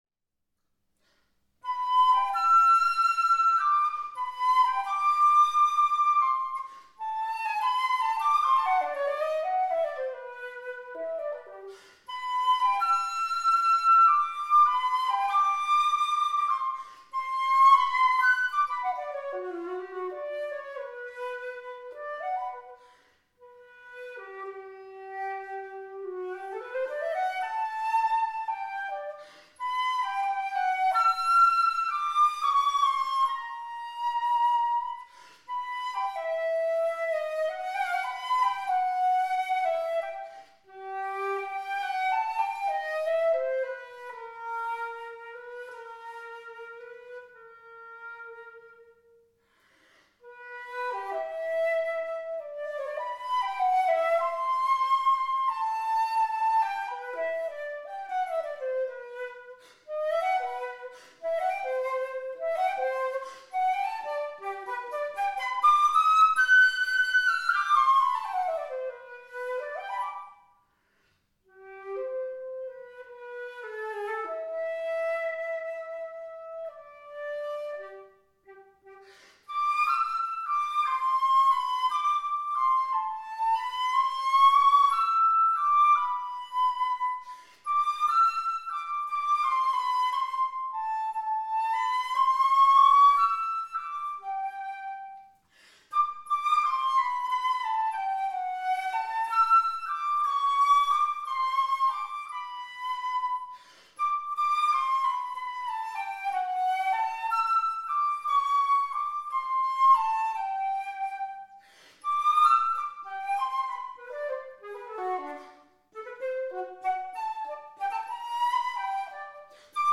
Conical ring-key flute – MARGUERITAT
Materials Cocuswood, nickel silver
This flute plays at a=440 Hz or slightly lower.
It has warm and very colourful lower notes.